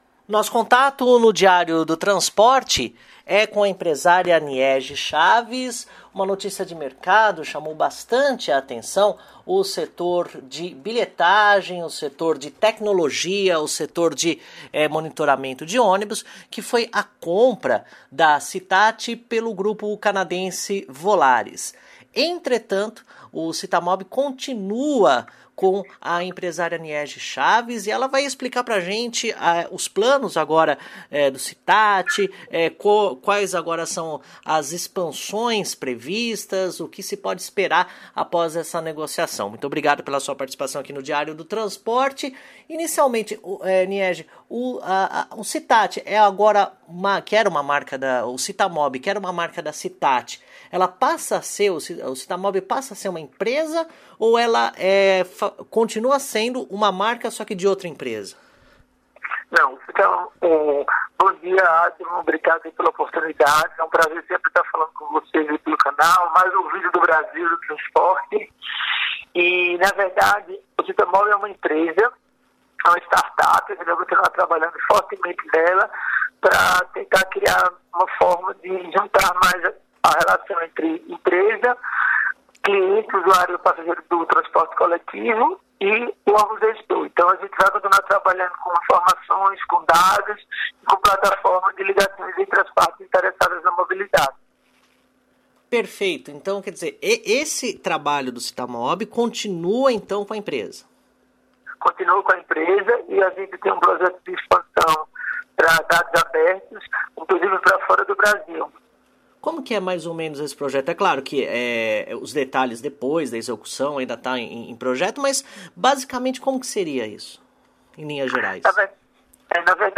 ENTREVISTA: Passageiros do Metrô e da CPTM devem ter programação das partidas no celular pelo aplicativo CittaMobi